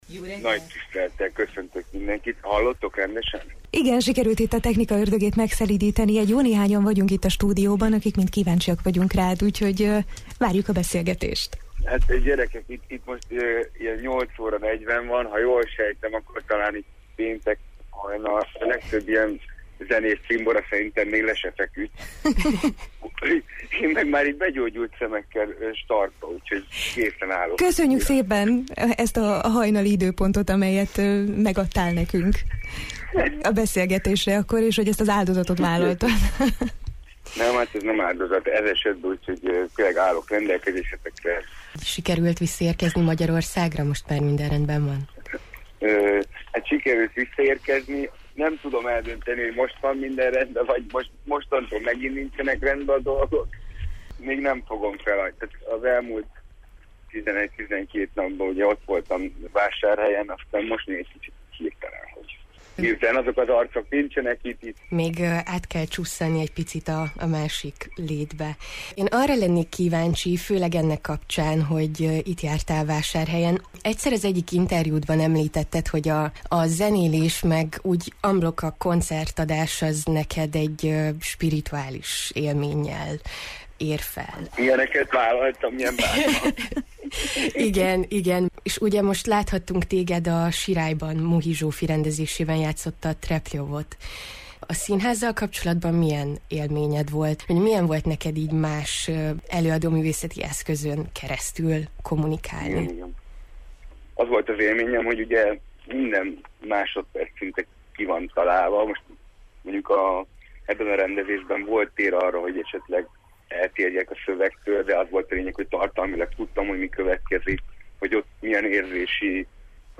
A mai Jó reggelt, Erdély-ben a marosvásárhelyi, szinházi közegben való felbukkanásának apropóján beszélgettünk, kitérve arra is, hogy miért kellene a számára felállított szobrot betonba önteni, illetve hova tekintsünk, ha vezetőket keresünk: